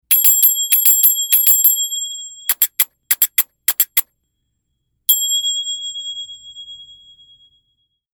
Long lasting medium tone, ideal for recording and to accent musical background.